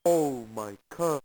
At the end of the first bonus game, the Mad Gear who cries about his car says different things depending on which version you're playing: